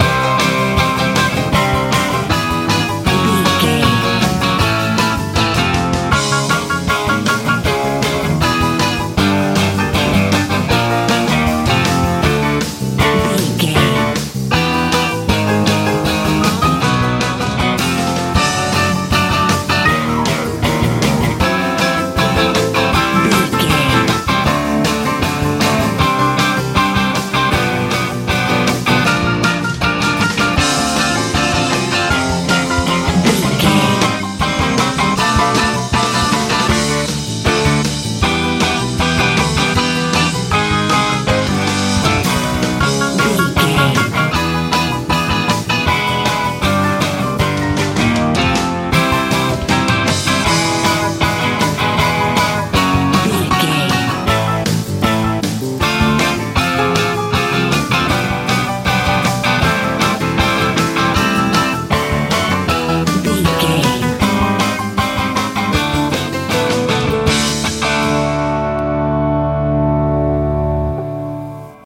rock n roll
Ionian/Major
driving
energetic
electric guitar
acoustic guitar
bass guitar
drums
piano
playful